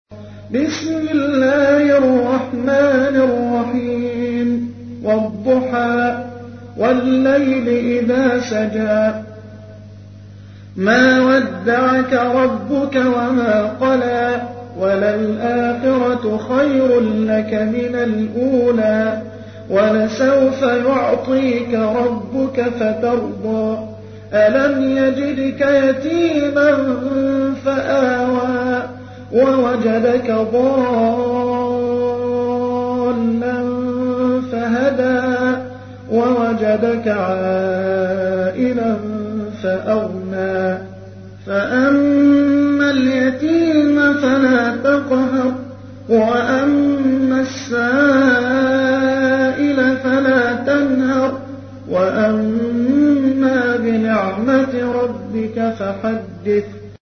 تحميل : 93. سورة الضحى / القارئ محمد حسان / القرآن الكريم / موقع يا حسين